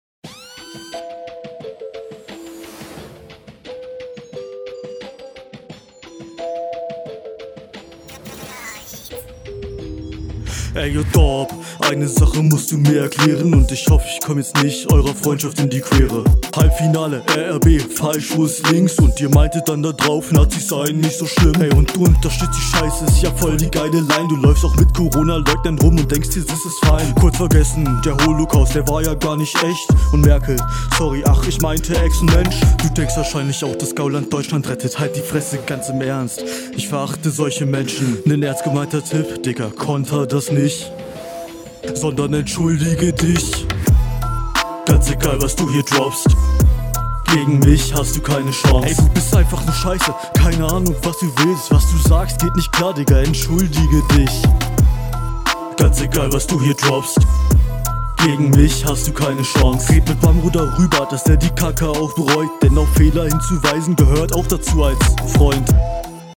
Flow: Nichts besonderes, aber gekonnt.
Flow:geiler einstieg kam echt fresh, anosnten grundsolide, paar variationen Text:antinazipart fand ich nice, ist ja …